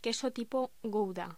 Locución: Queso tipo gouda
voz
Sonidos: Voz humana